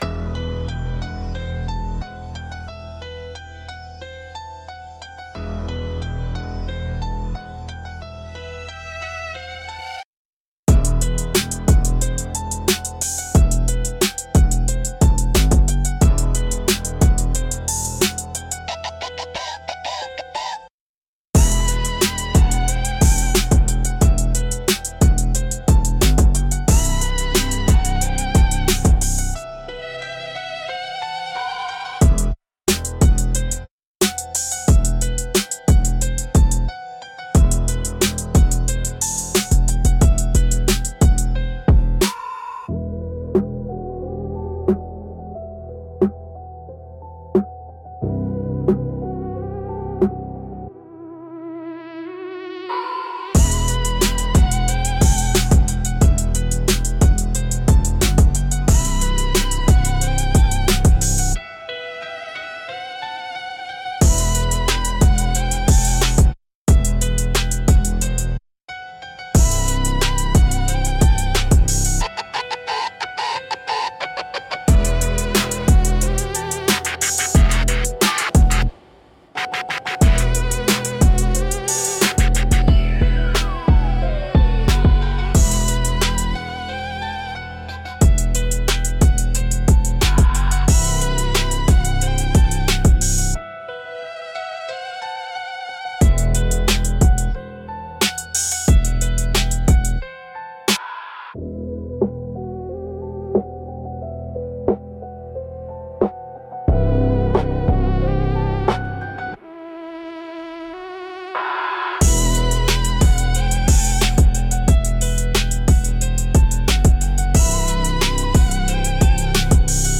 Instrumentals - Streetlight Interlude